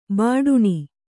♪ bāḍuṇi